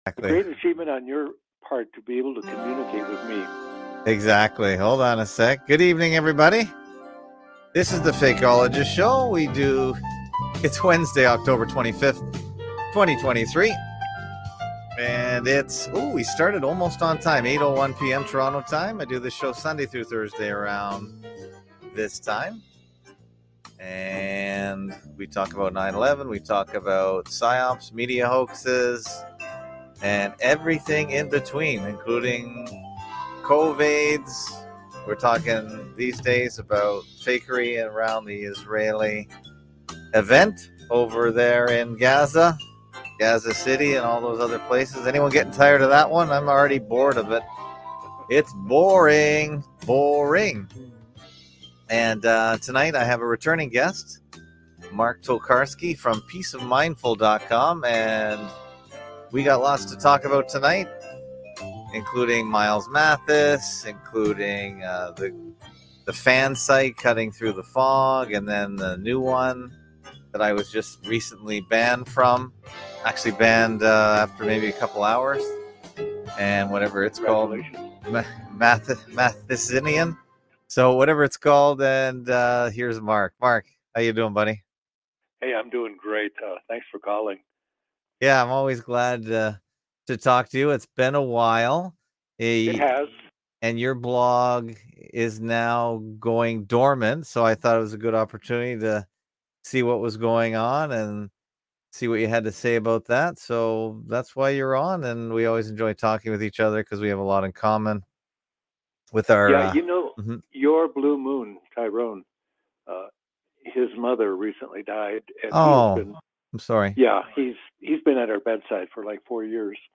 Category: Live Stream